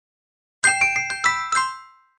JVCのティンクル1が流れていた。